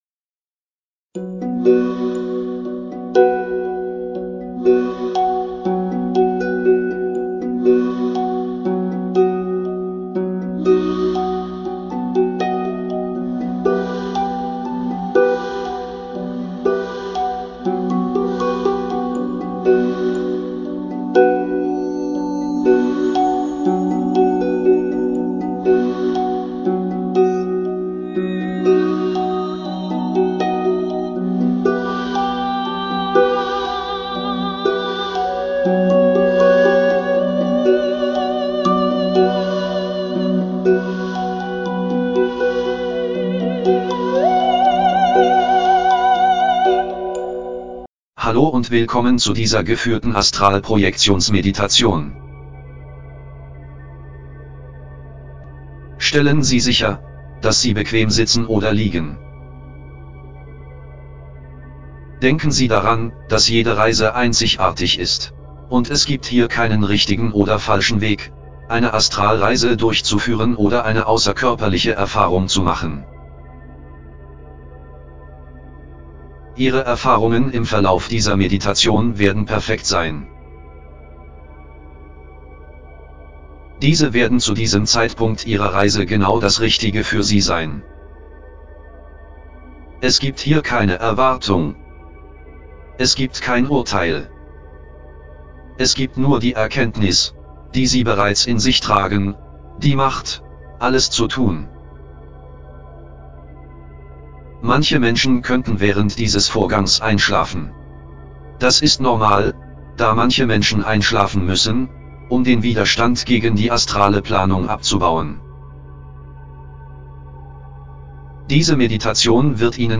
Außerkörperliche gesprochene Visualisierungs-Meditations-Erfahrung Denken Sie daran, dass jede Reise einzigartig ist.
OBEOutOfBodySpokenVisualizationMeditationExperienceDE.mp3